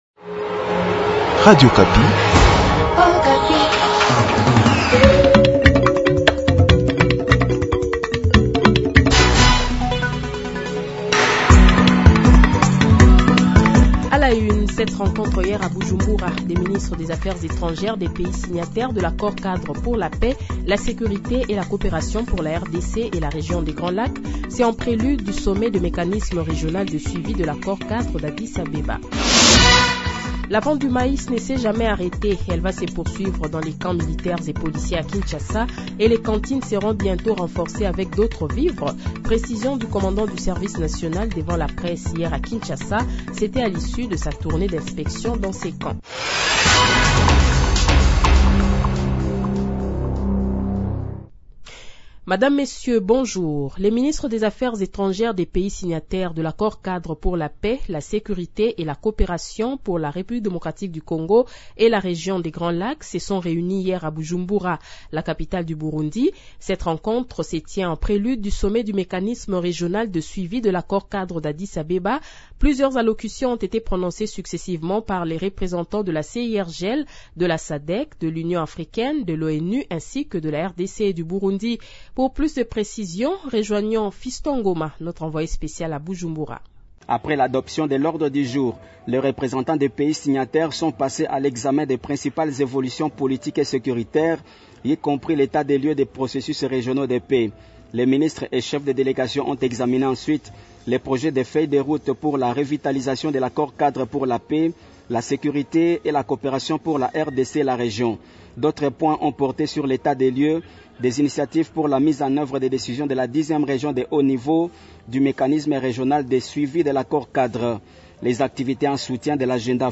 Le Journal de 7h, 05 Mai 2023 :